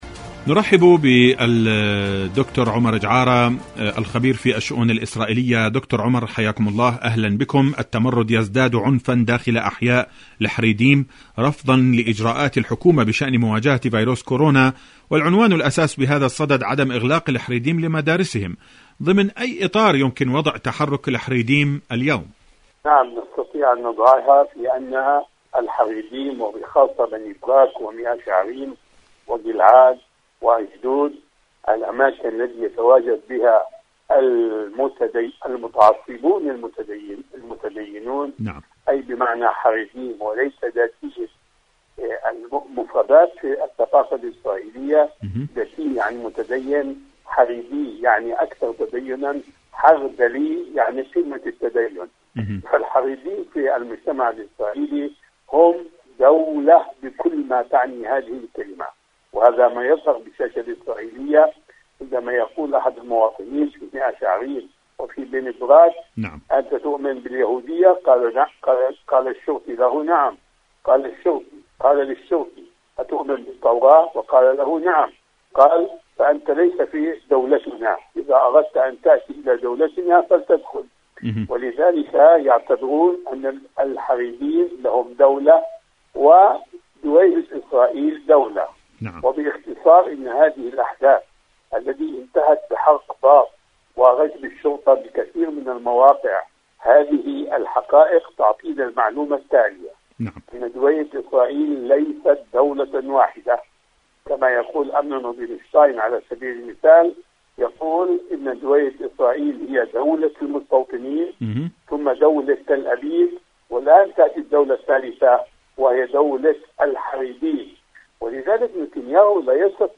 مقابلات برامج إذاعة طهران برنامج فلسطين اليوم القدس الشريف المسجد الأقصى كيان الاحتلال مقابلات إذاعية الحراك الحريدي والعلماني الكيان إلى أين؟